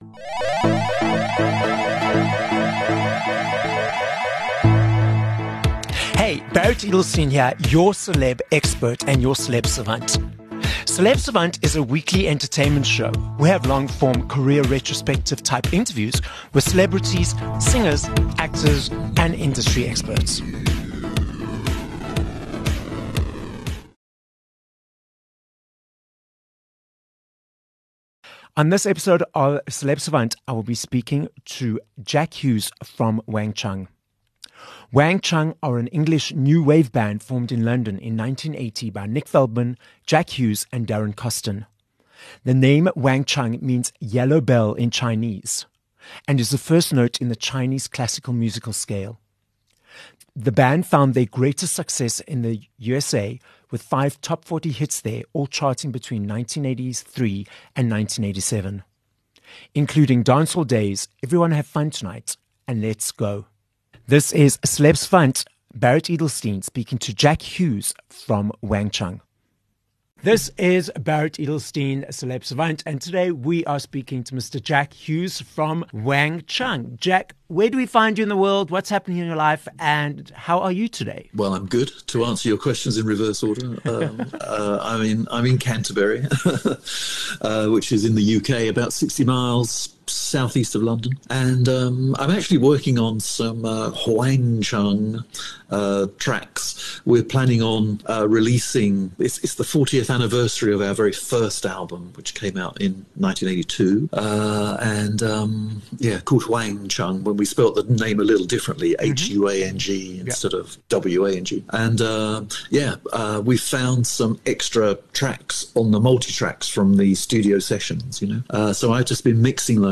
31 Jul Interview with Jack Hues from Wang Chung